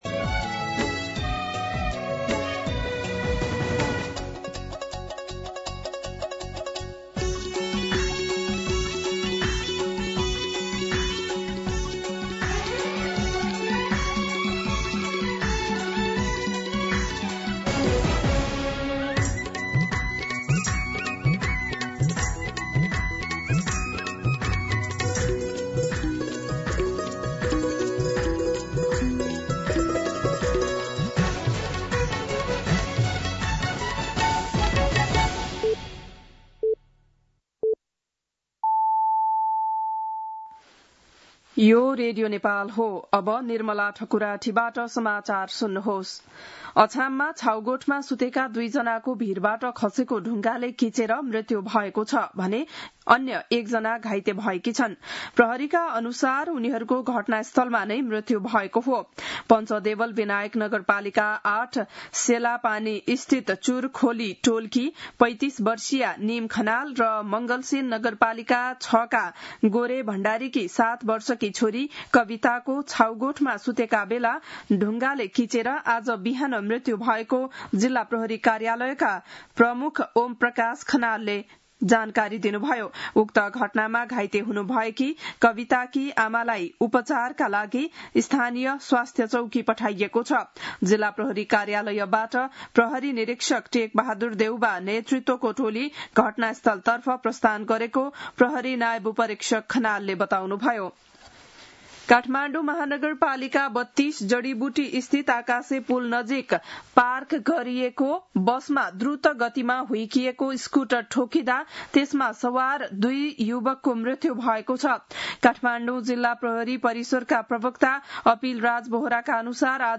बिहान ११ बजेको नेपाली समाचार : १३ भदौ , २०८२